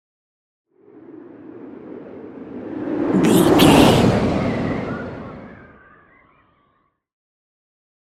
Whoosh airy long
Sound Effects
futuristic
intense
whoosh